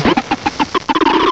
cry_not_hippopotas.aif